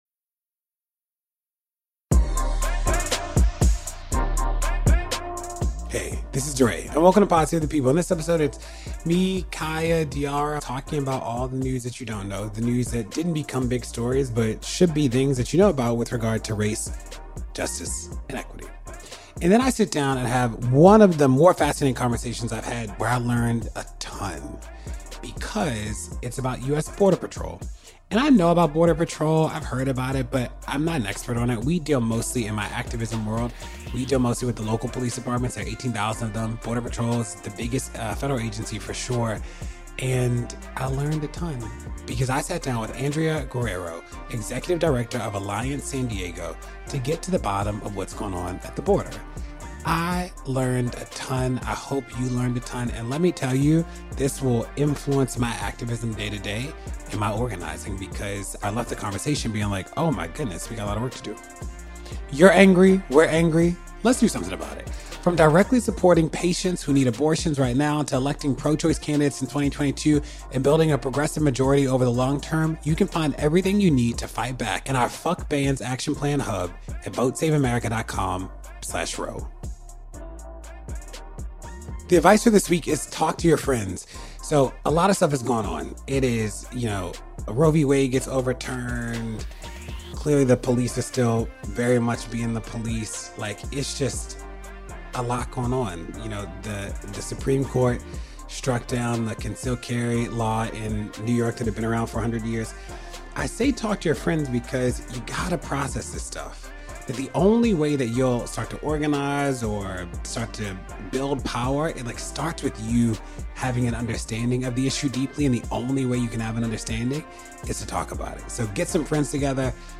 interviews leader and activist